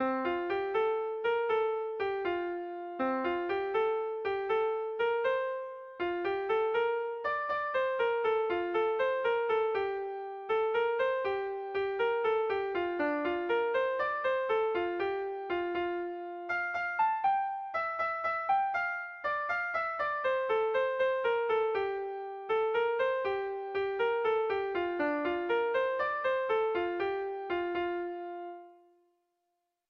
Sentimenduzkoa